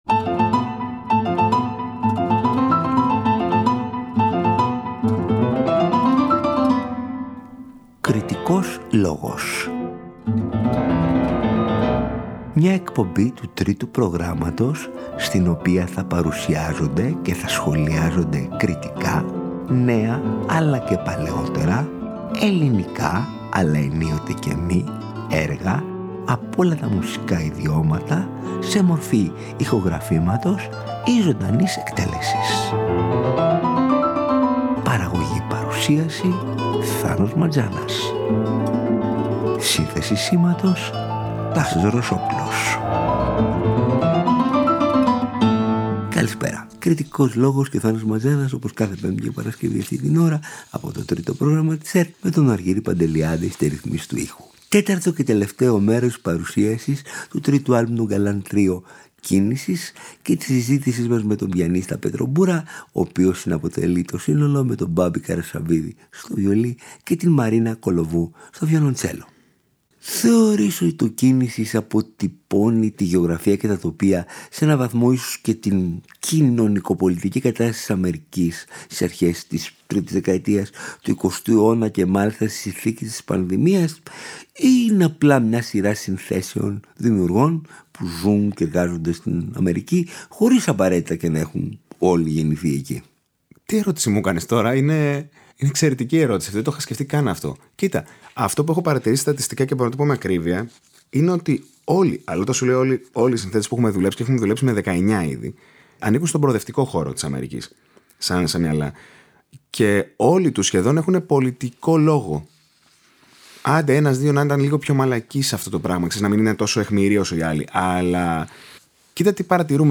Διακρίνονται για την πολύ μεγάλη μεταξύ τους υφολογική και στιλιστική ευρύτητα, από πιάνο τρίο της σπουδαίας παράδοσης του ρομαντισμού και της προγραμματικής μουσικής μέχρι τις πιο σύγχρονες τάσεις και την ατονικότητα.